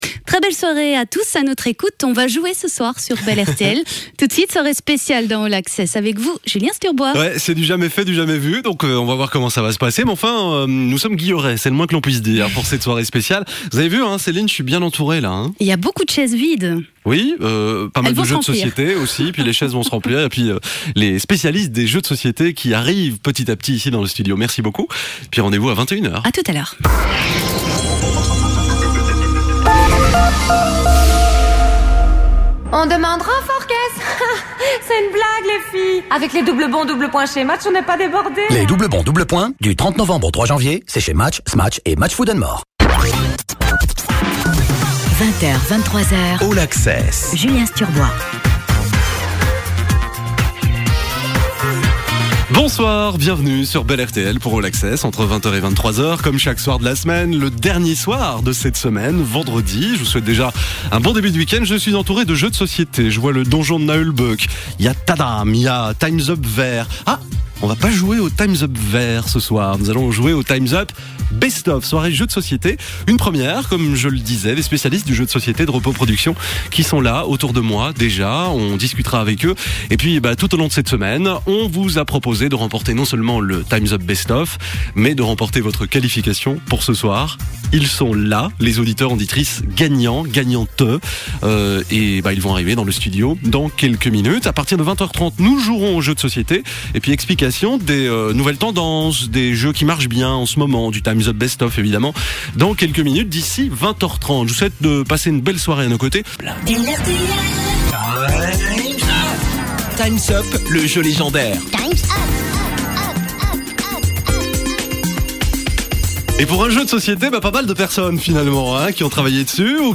Une partie de Time's up en direct sur la radio belge Bel ...